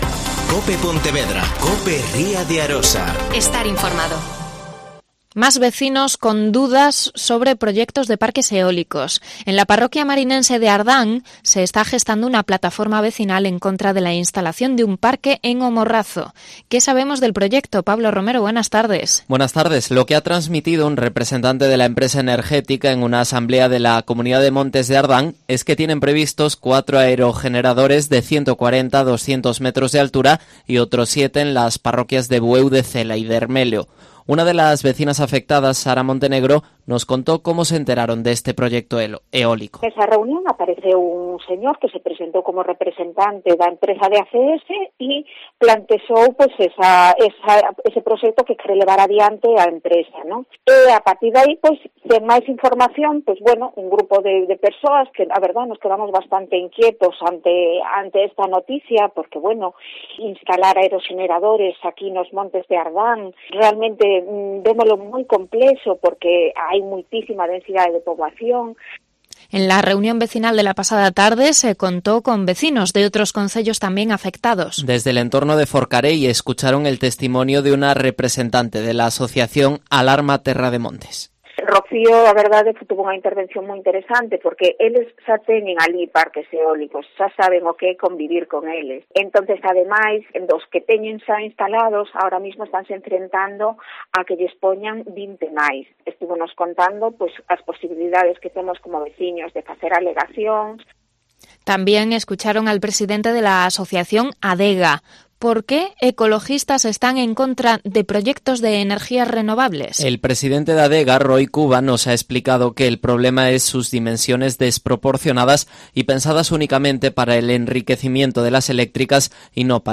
Informativo Mediodía COPE sobre proyectos de parques eólicos en O Morrazo